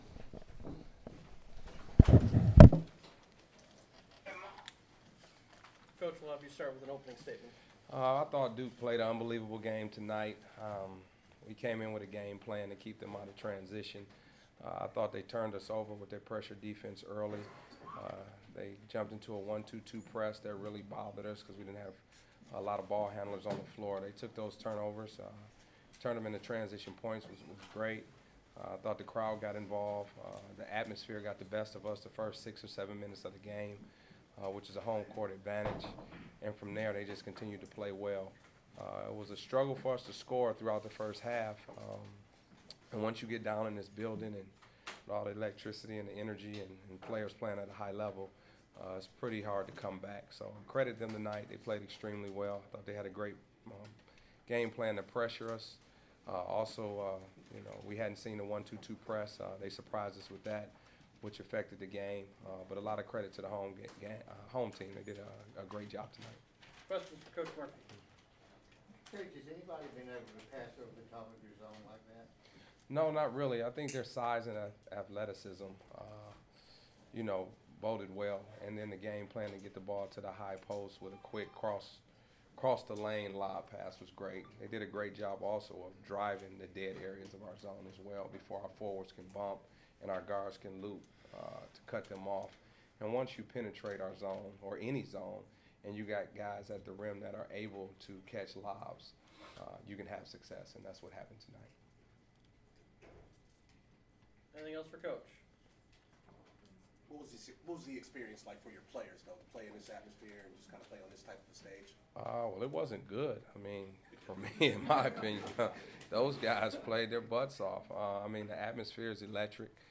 Postgame Audio.